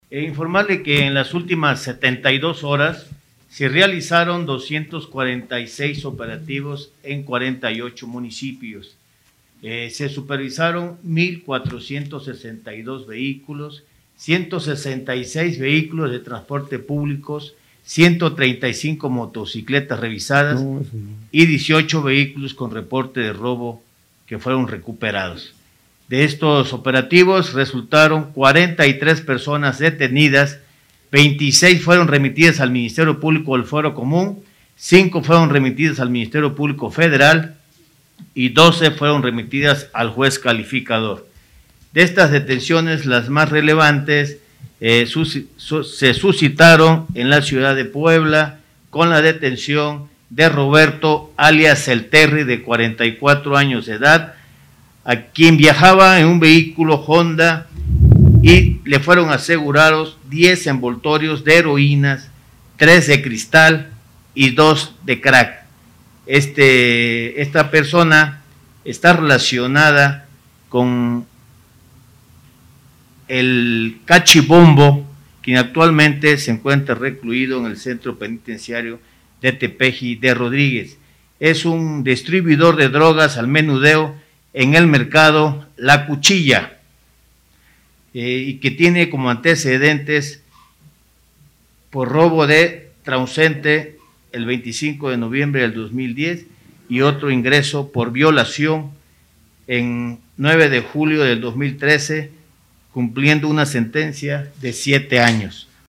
Por su parte, el secretario de Seguridad Pública, Raciel López Salazar, dio a conocer que la dependencia a su cargo realizó 246 operativos en 48 municipios durante las últimas 72 horas.